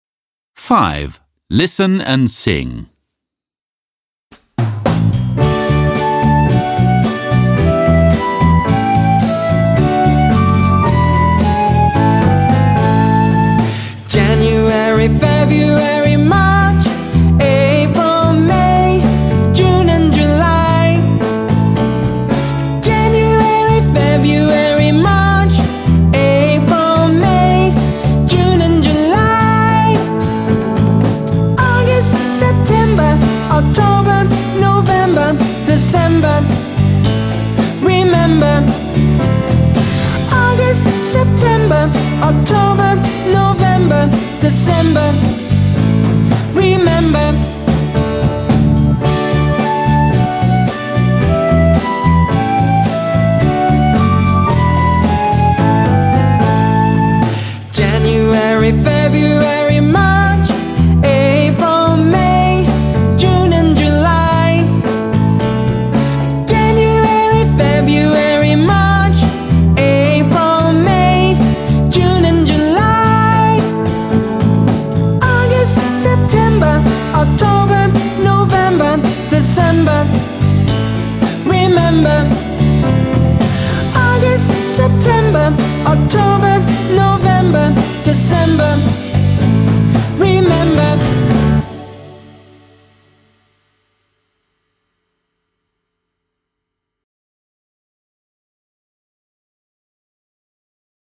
Muistatko vielä kuukausilaulun? Kyllä, juuri sen ihanan korvamadon.